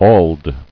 [auld]